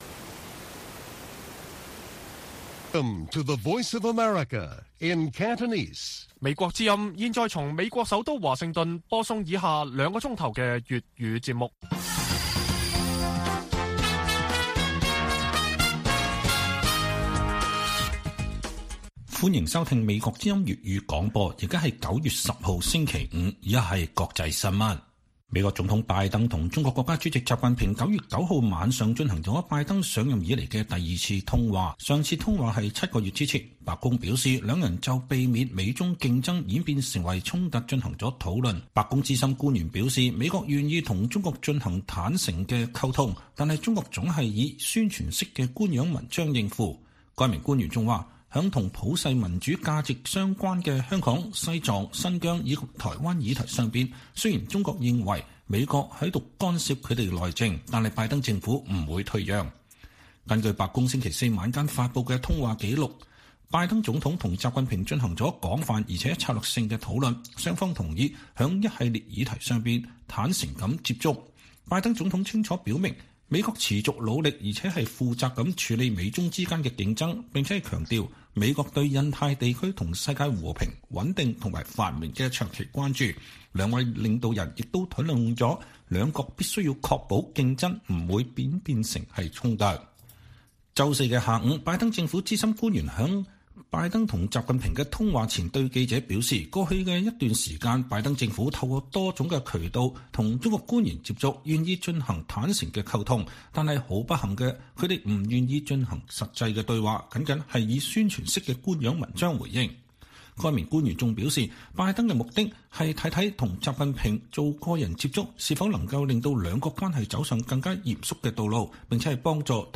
粵語新聞 晚上9-10點: 支聯會3名領袖被控煽動顛覆10月再訊